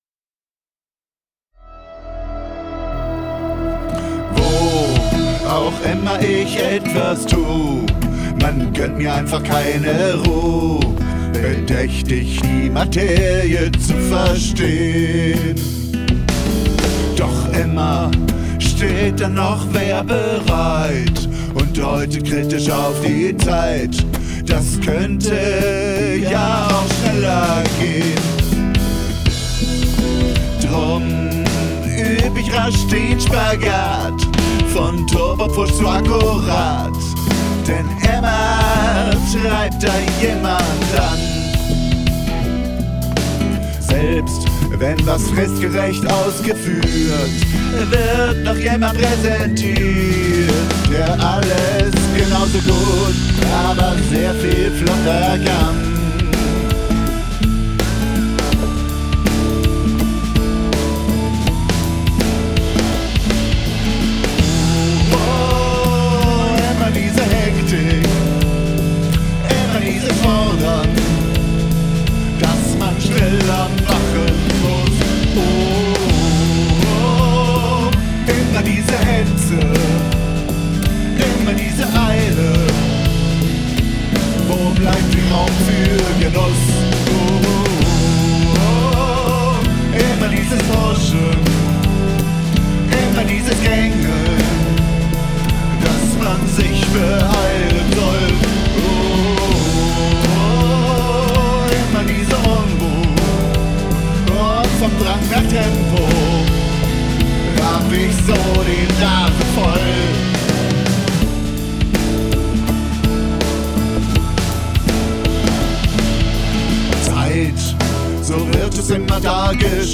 [pop]